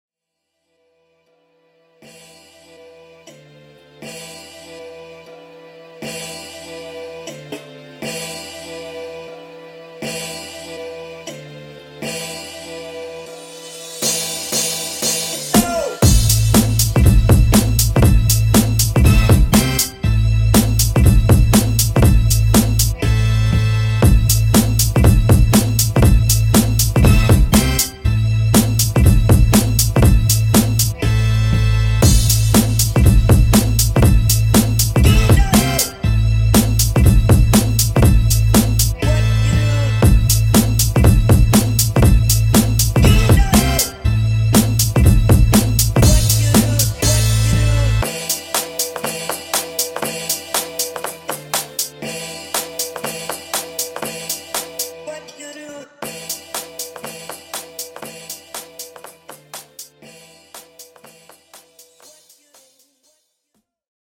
Dope drums and soulful samples